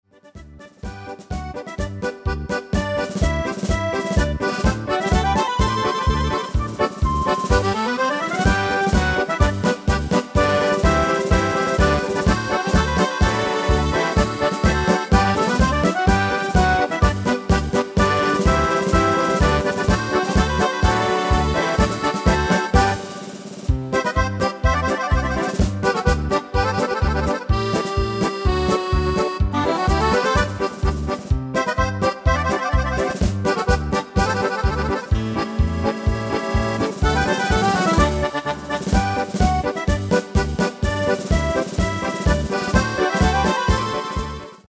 Demo/Koop midifile
Genre: Nederlandse Oldies
Toonsoort: G
Demo = Demo midifile
Demo's zijn eigen opnames van onze digitale arrangementen.